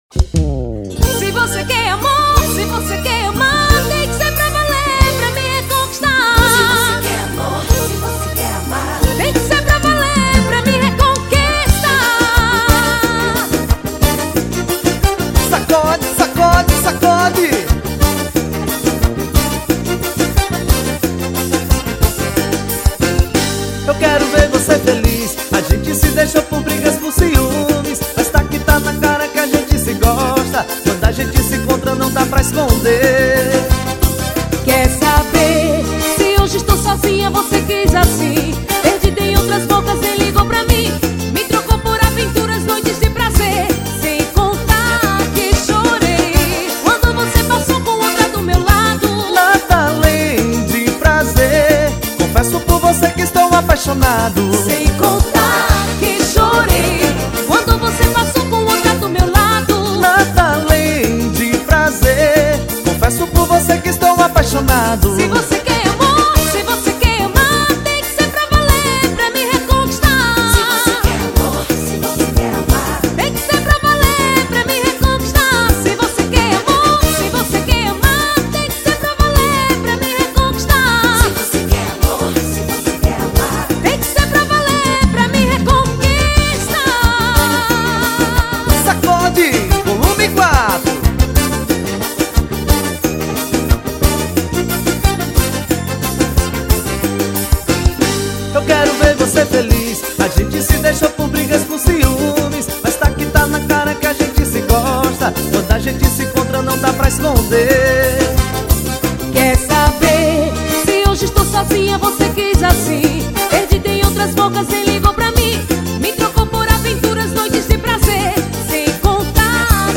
2024-12-29 14:11:55 Gênero: Forró Views